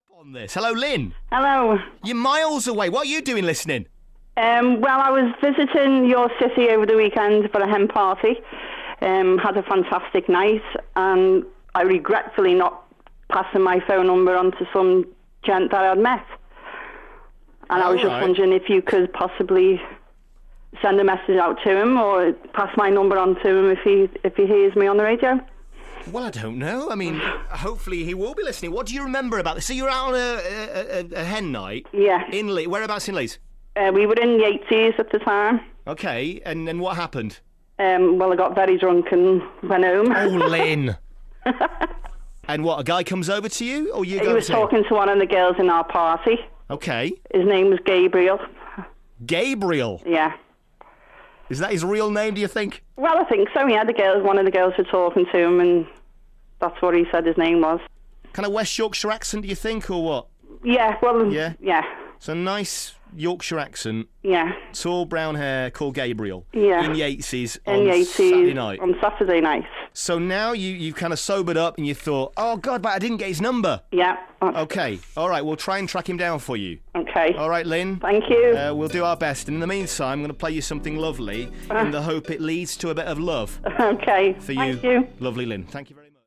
A listener from Liverpool called